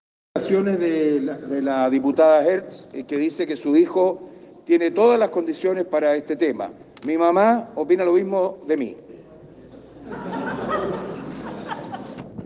“Dice que su hijo tiene todas las condiciones para este tema… mi mamá opina lo mismo de mí”, señaló, causando risa entre quienes estaban en el punto de prensa.